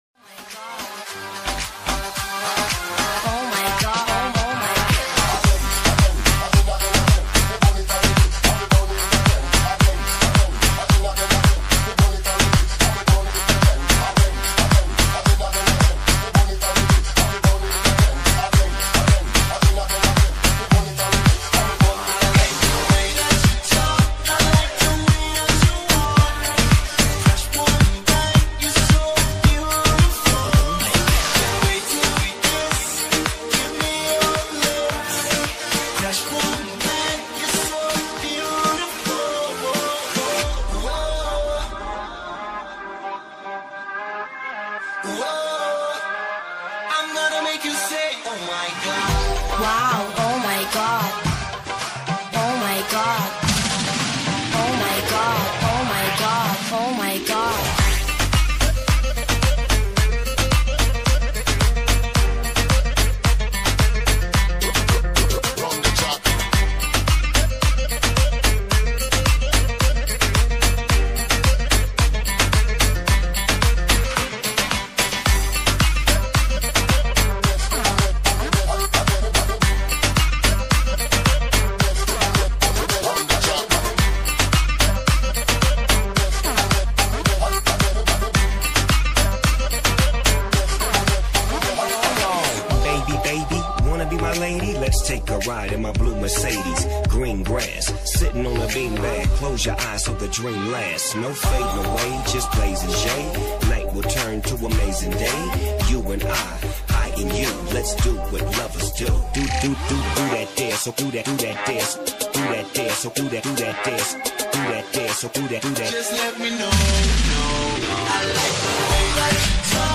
__Klassnaya_Arabskaya_Muzyka__MP3_128K.mp3